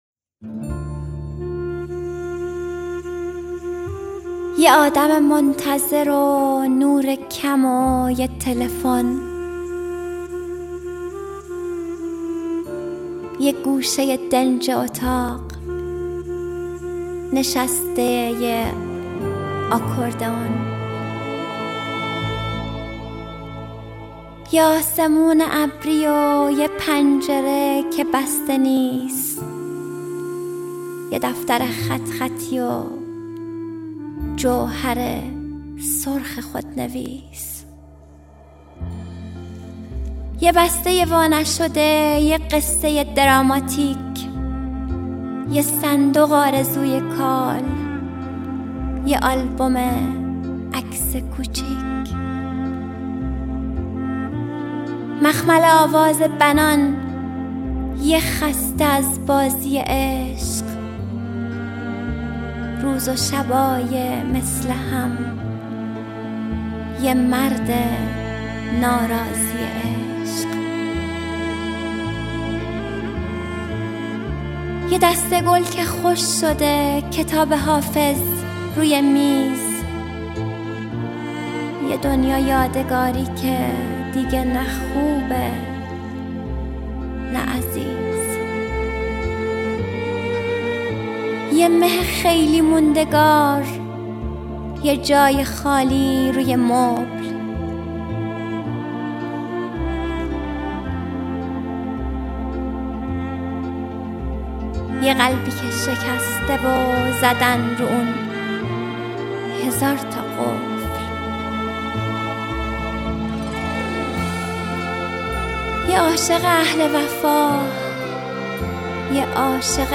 دانلود دکلمه یکی بود یکی نبود با صدای مریم حیدرزاده با متن دکلمه
دانلود نسخه صوتی دکلمه دانلود / گوینده: [مریم حیدرزاده]